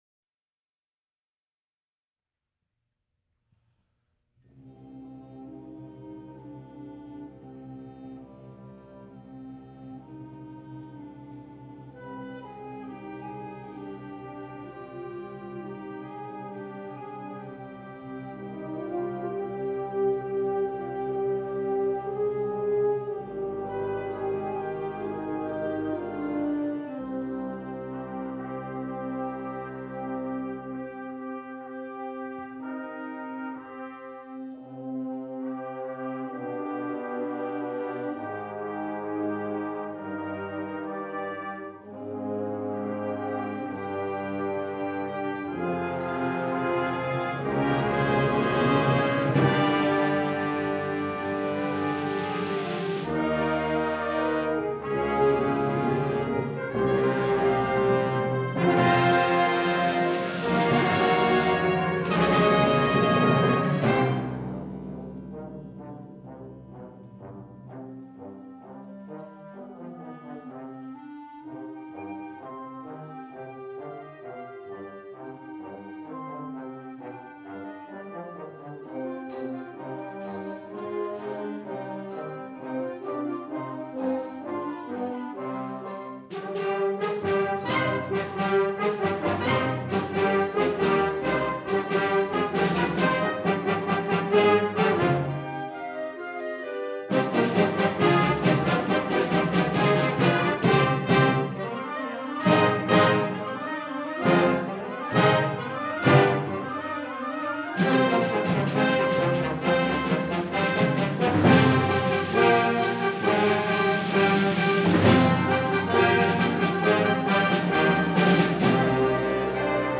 Genre: Band